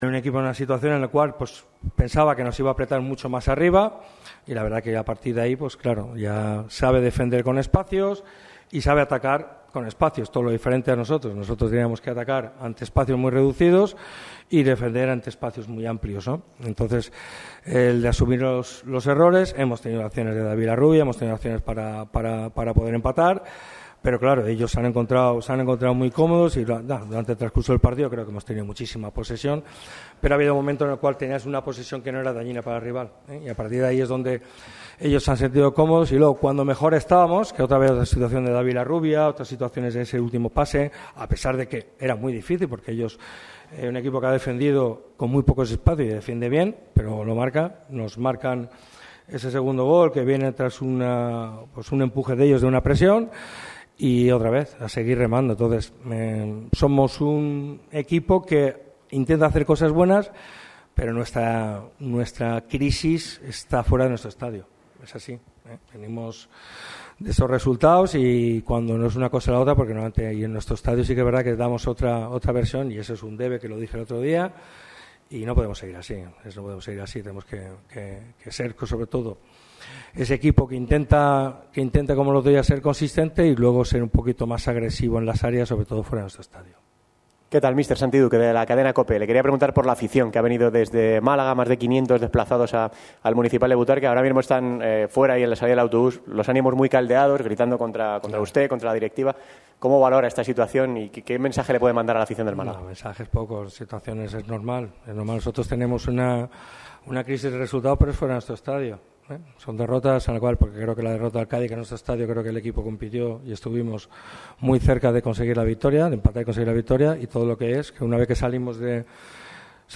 Tras la derrota contra el conjunto pepinero, el técnico ha comparecido en rueda de prensa haciendo hincapié en al debilidad del equipo fuera de casa.